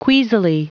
Prononciation du mot queasily en anglais (fichier audio)
Prononciation du mot : queasily